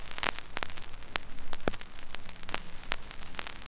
FX (3).wav